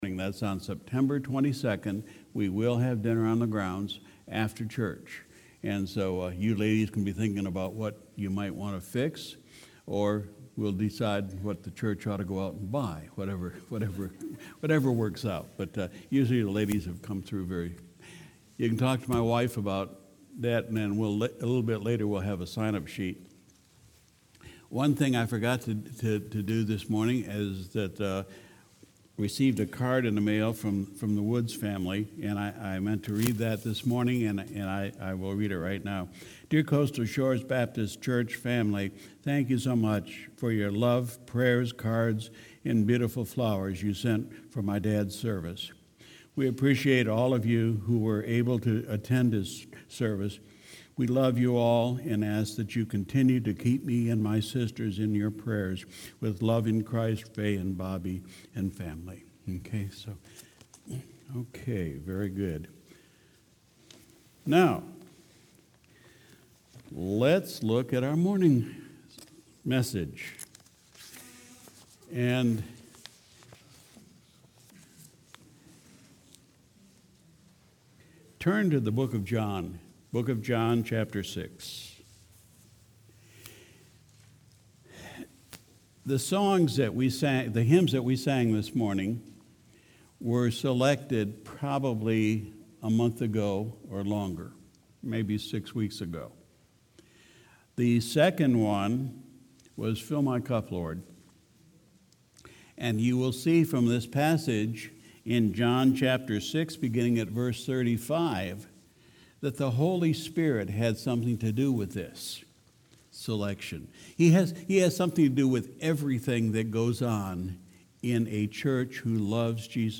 Sunday, August 25, 2019 – Morning Service